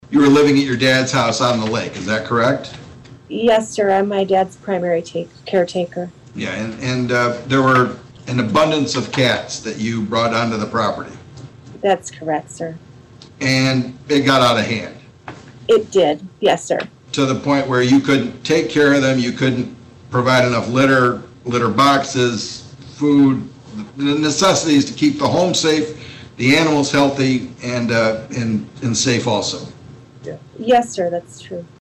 A plea hearing was held on Thursday in front of St. Joseph County Circuit Court Judge Paul Stutesman.